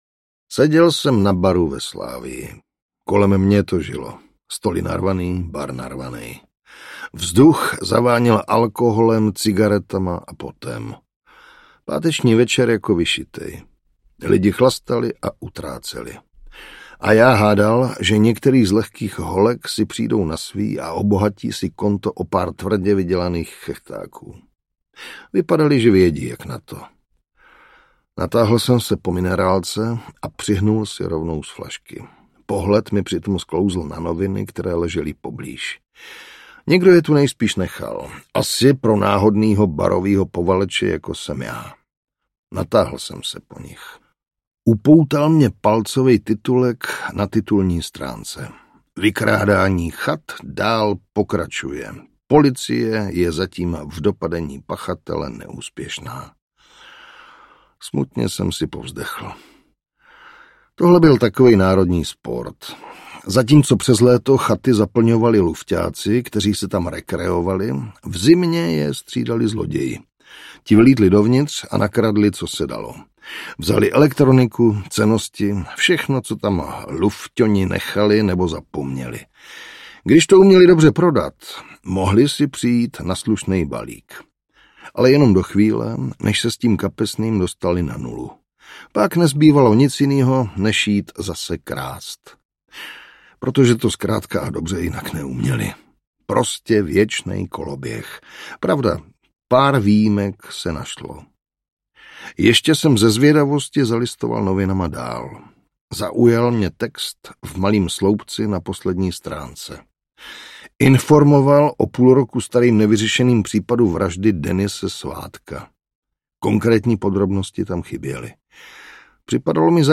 Hříchy otců audiokniha
Ukázka z knihy
Čte Jiří Vyorálek.
Vyrobilo studio Soundguru.